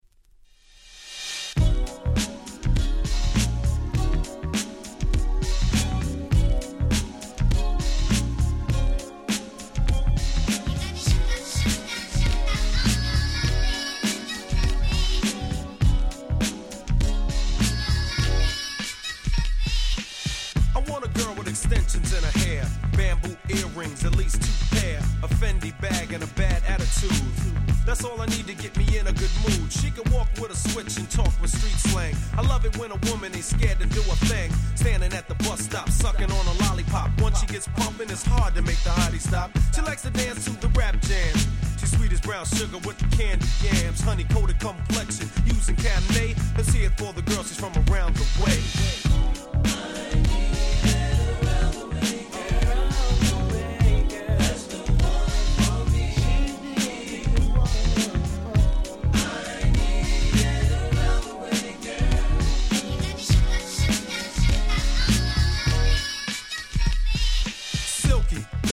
90' Big Hit Hip Hop !!